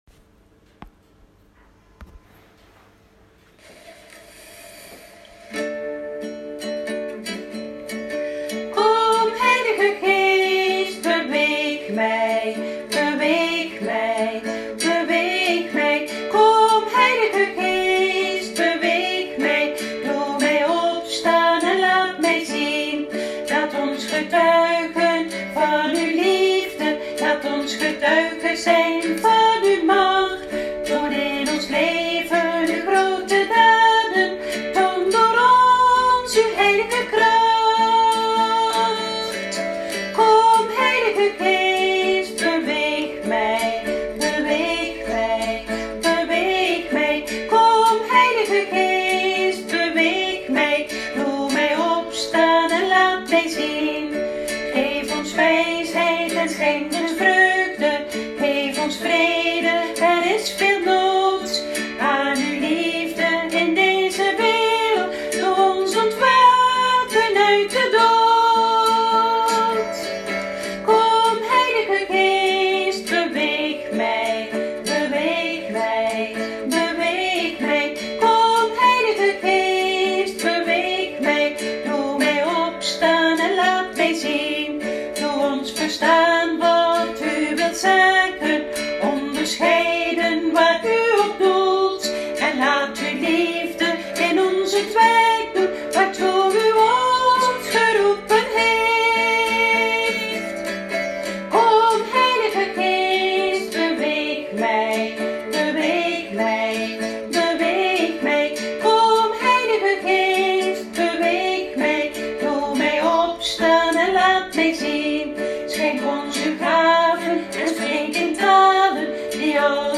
Themalied CHARIS-dag 2023